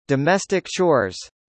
「domestic chores」の英語発音を聞いてみましょう。